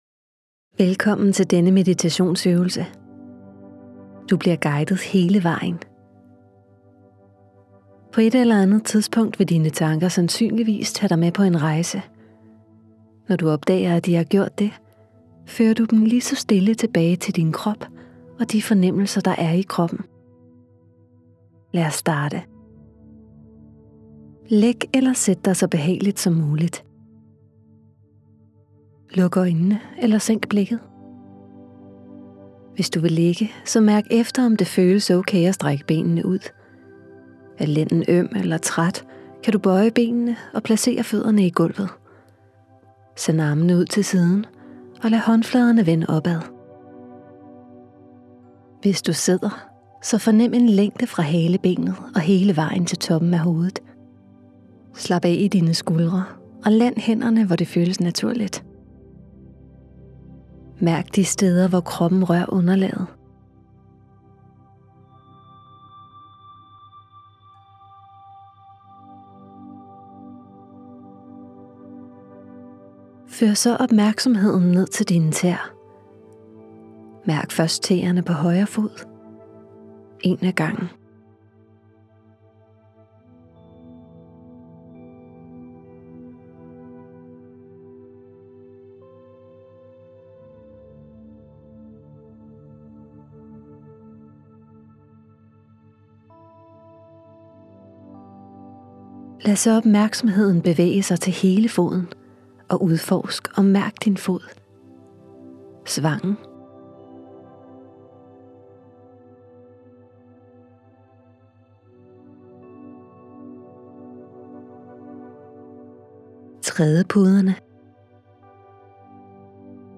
Kropsscanning – meditationsøvelse med lydguide
Lyt til en guidet meditation her.